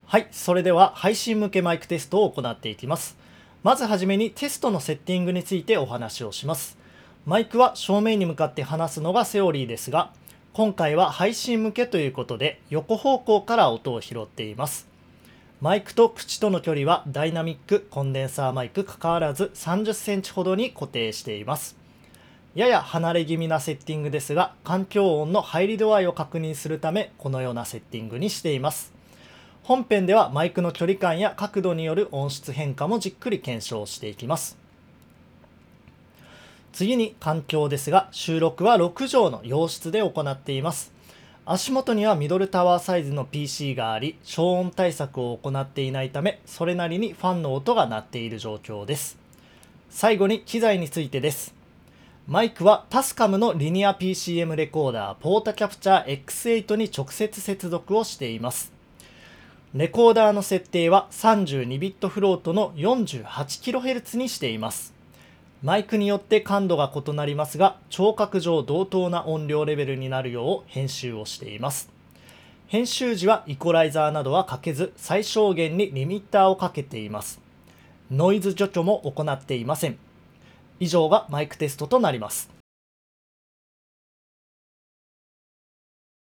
イコライザーやノイズ除去は一切行っていない生々しいサンプル音源を公開致します。
収録は、配信を想定しマイクと口との距離は30cm程と離れ気味にセット。
ポップガードは使用していません。
RODE BROADCASTER(コンデンサー/エンドアドレス型)